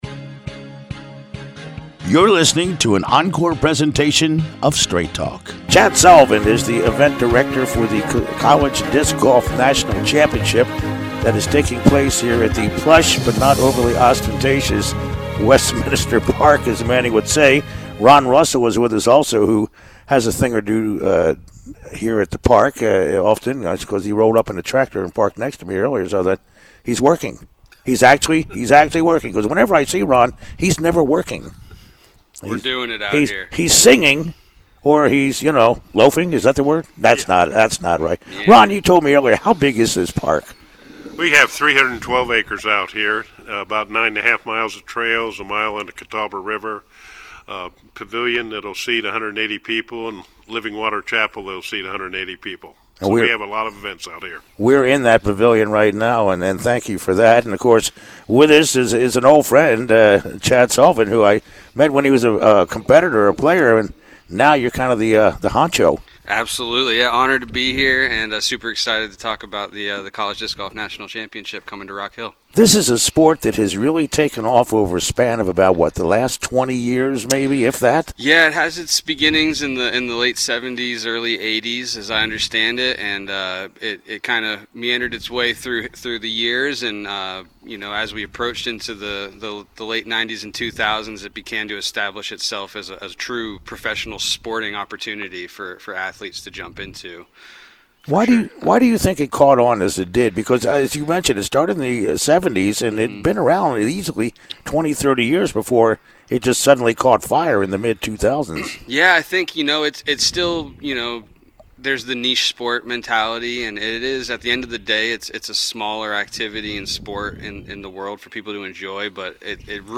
Live from Westminster Park for the Collegiate Disc Golf Tournament.